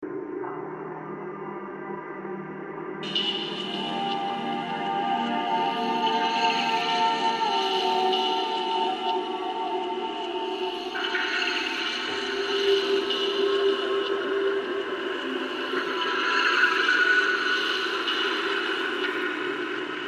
描述：哀伤的空间填充物
Tag: 120 bpm Ambient Loops Fx Loops 3.37 MB wav Key : Unknown